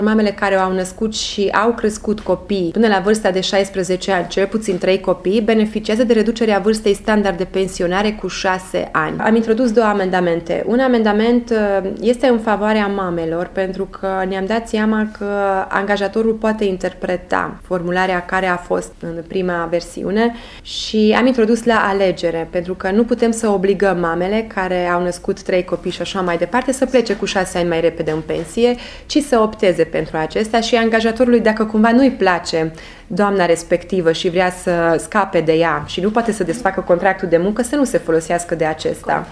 Deputatul UDMR Csep Eva Andrea, a declarat azi, la Tg.Mureș, că mamele vor putea opta pentru dacă vor dori să iasă mai repede în pensie: